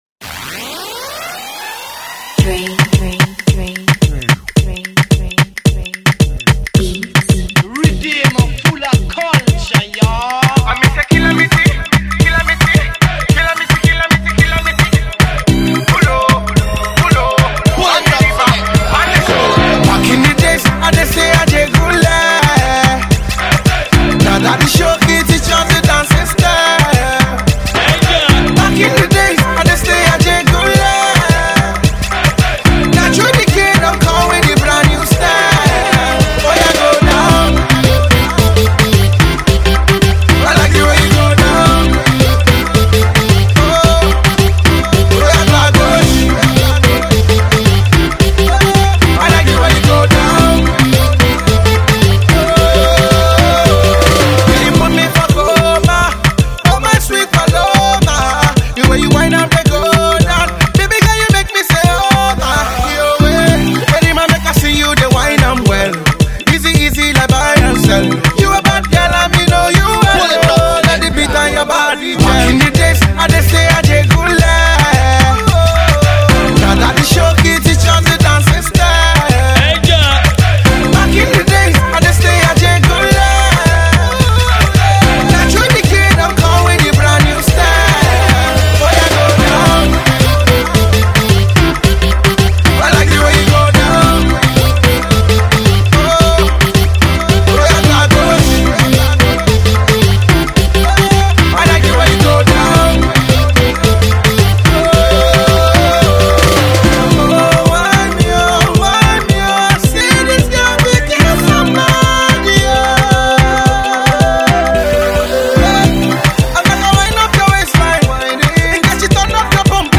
With the Galala sound back and in full effect